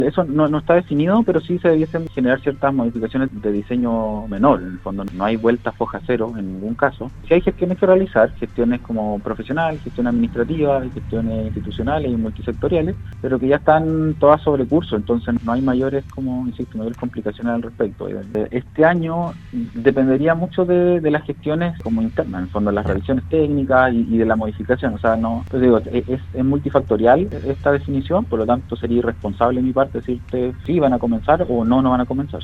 En conversación con Radio Sago, el nuevo Seremi de Vivienda y Urbanismo de la región de Los Lagos, Fabián Nail, aseguró que se está trabajando para avanzar en la etapa 2 y 3 del Predio Baquedano de Osorno.